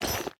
Minecraft Version Minecraft Version latest Latest Release | Latest Snapshot latest / assets / minecraft / sounds / item / armor / equip_diamond3.ogg Compare With Compare With Latest Release | Latest Snapshot
equip_diamond3.ogg